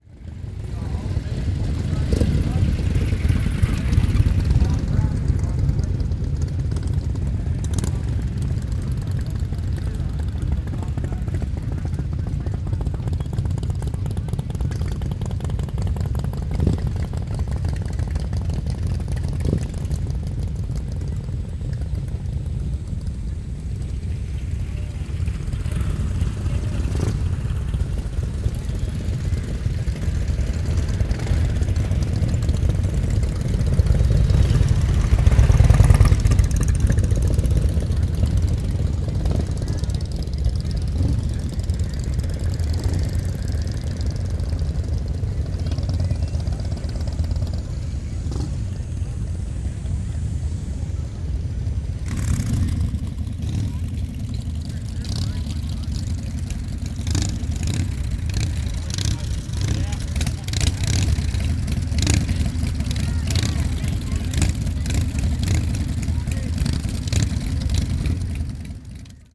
Звуки мотоцикла
Рев моторов и гул байкеров на площади